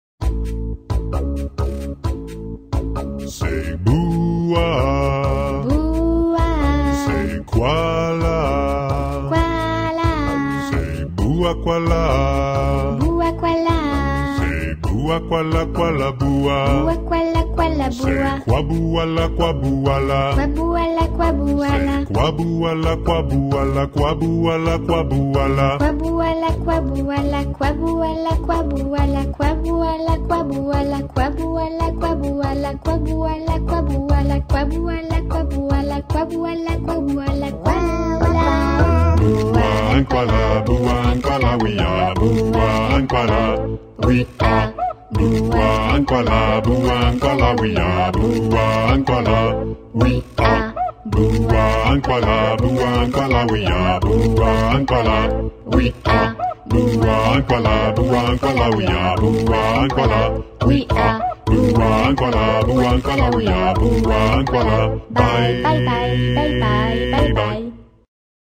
Category: Theme songs